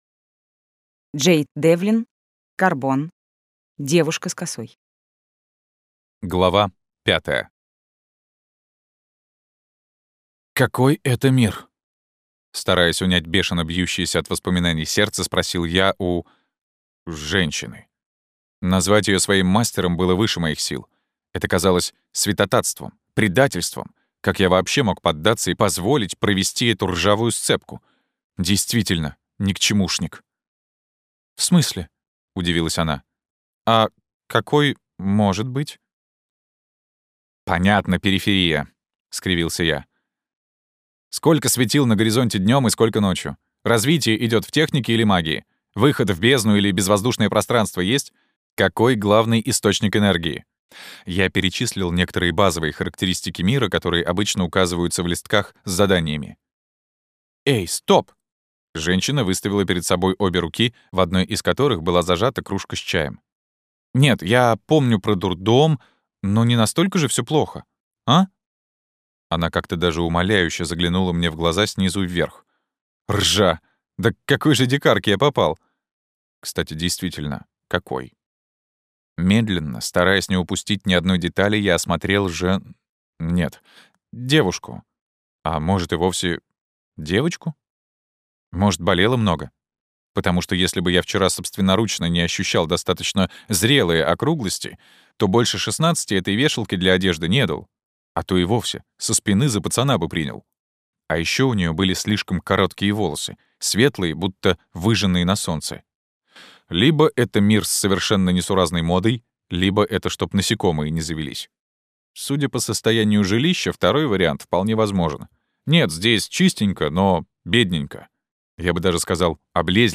Аудиокнига Девушка с Косой | Библиотека аудиокниг
Прослушать и бесплатно скачать фрагмент аудиокниги